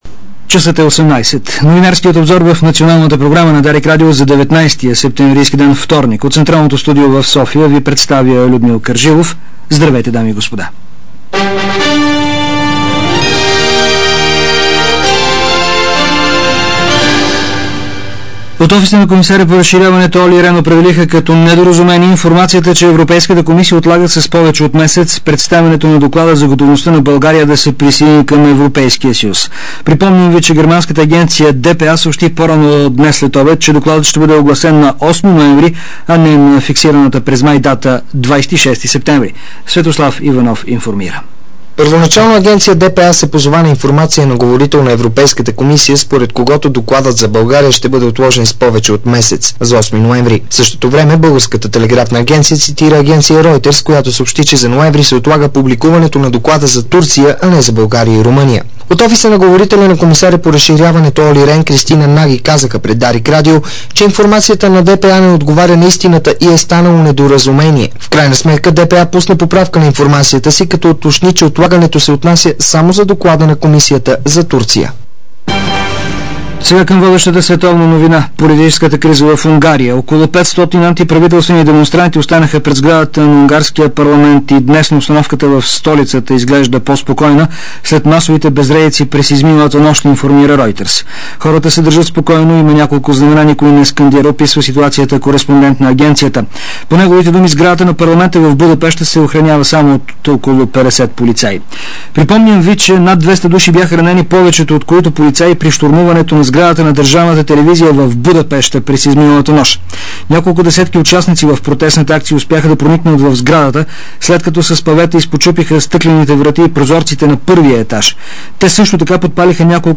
DarikNews audio: Обзорна информационна емисия 02.05.2006